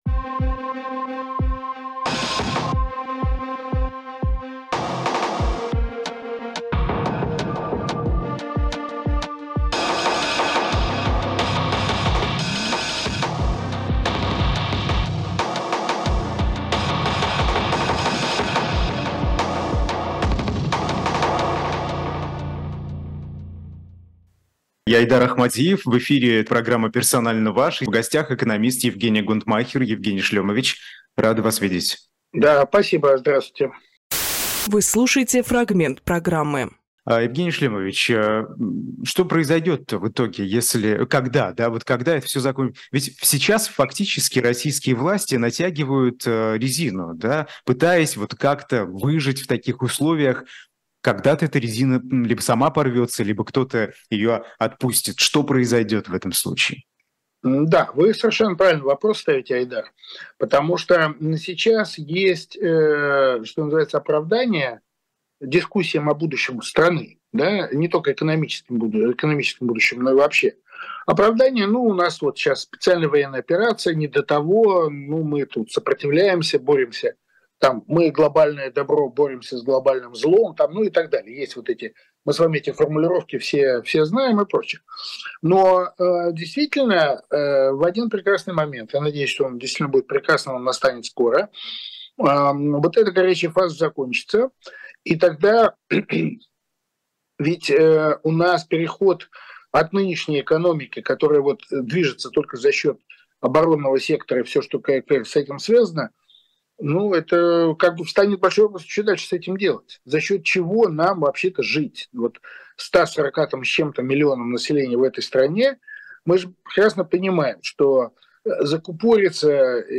Фрагмент эфира от 31.10.23